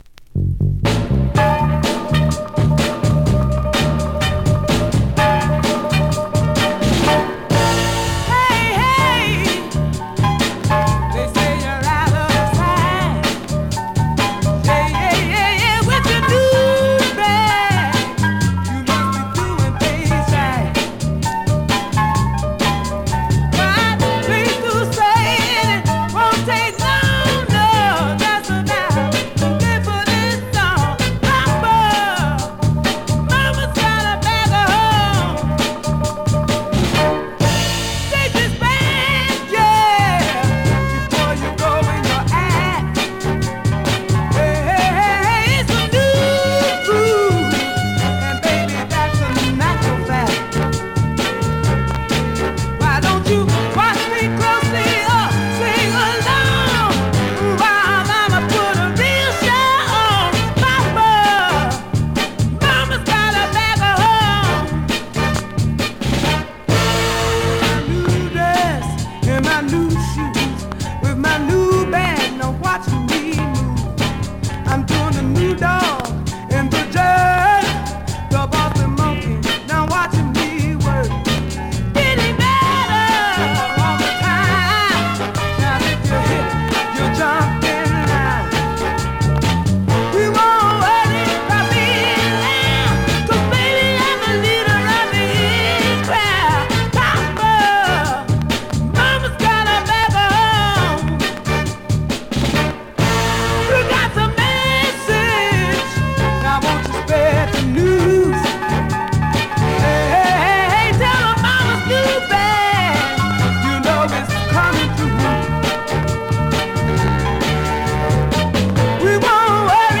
Great mid-tempo Northen / Rnb dancer
Categories: R&B, MOD, POPCORN , SOUL